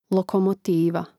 lokomotíva lokomotiva